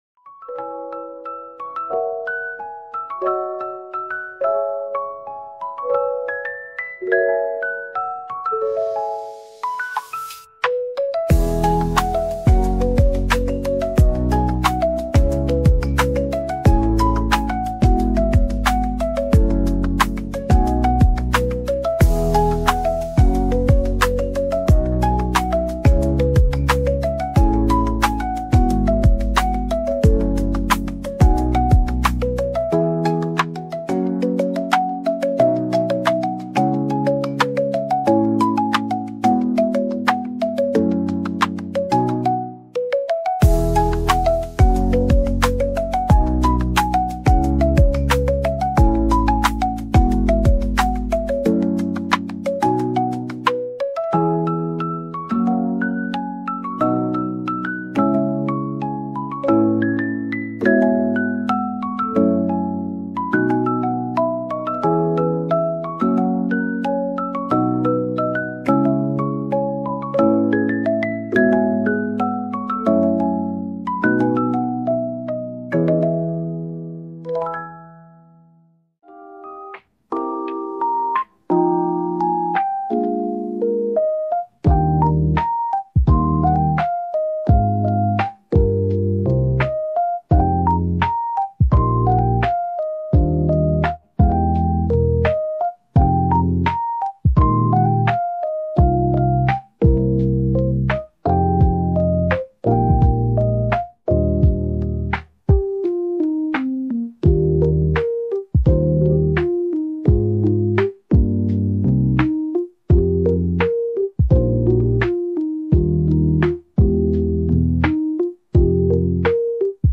lo-fi music